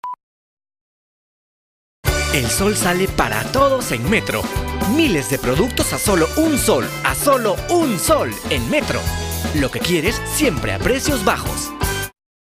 Ich habe mein eigenes Studio.